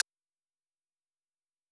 Hi Hat [Fruity].wav